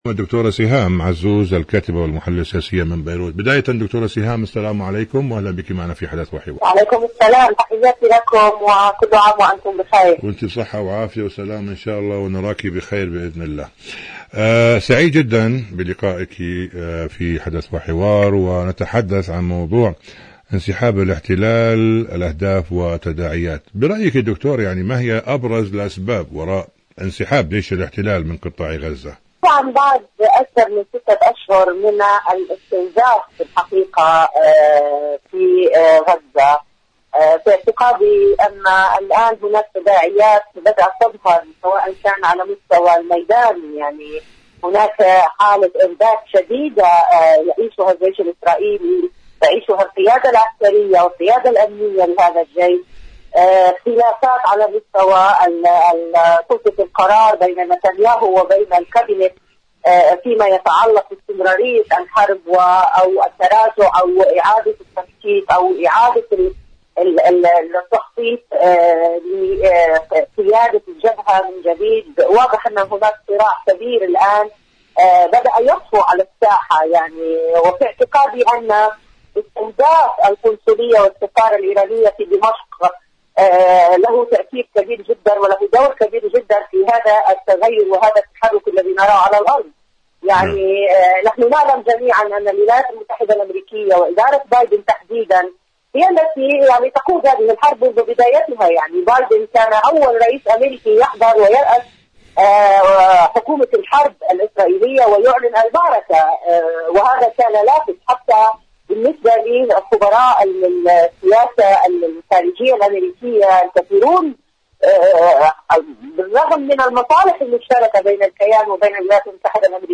مقابلات برنامج حدث وحوار حدث وحوار غزة الاحتلال الإسرائيلي برامج إذاعة طهران العربية التداعيات انسحاب انسحاب الاحتلال الأسباب فلسطين شاركوا هذا الخبر مع أصدقائكم ذات صلة إيران تعري الغطرسة الصهيوأميركية في المنطقة..